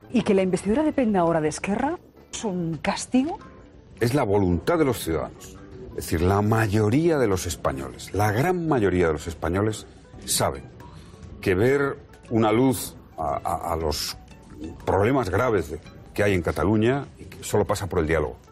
Declaraciones de José Luis Rodríguez Zapatero